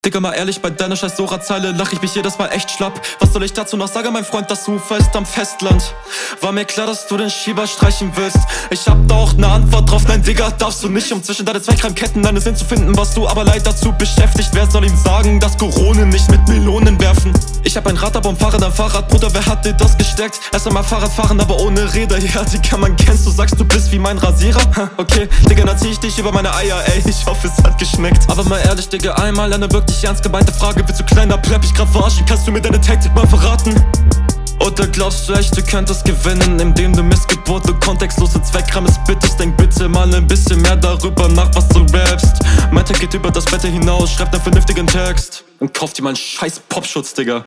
Guter Flow.